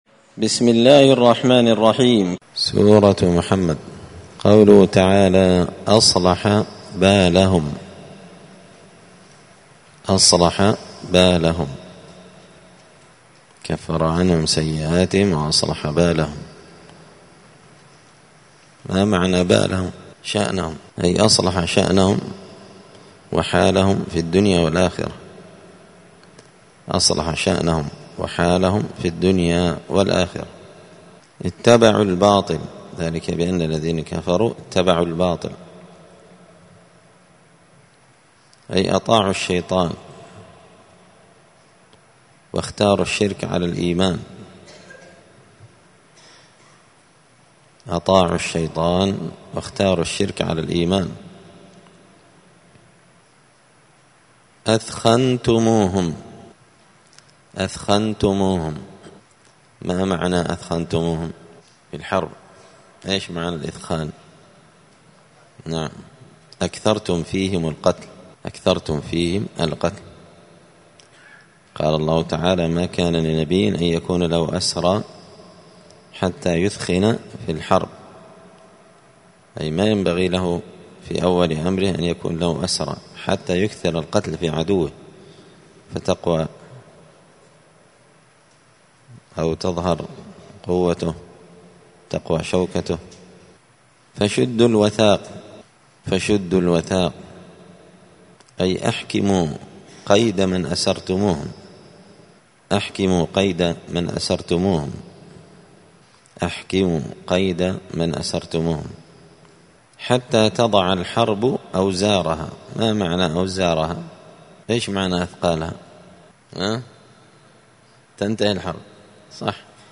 دار الحديث السلفية بمسجد الفرقان بقشن المهرة اليمن